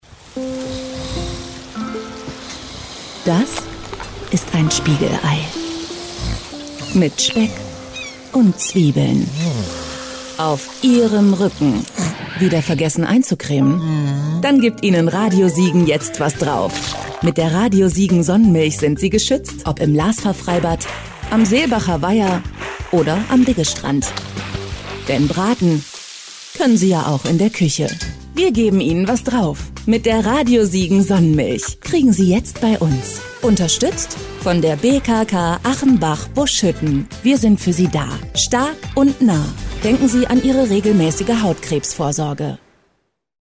Sprecherin, Werbesprecherin, Hörspiel-Sprecherin,Mikrofonstimme
Kein Dialekt
Sprechprobe: Sonstiges (Muttersprache):